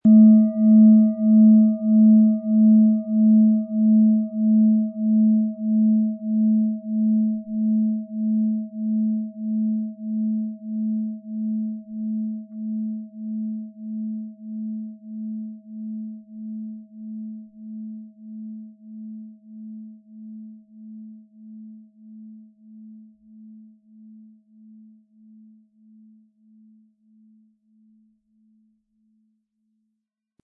Eine antike Klangschale mit einzigartiger Tiefe - Für sanfte Meditation und Harmonie
Ihre sanfte, klare Resonanz wächst mit jedem behutsamen Anspiel, wird zu einem treuen Begleiter, der Ihnen harmonische Klänge schenkt.
MaterialBronze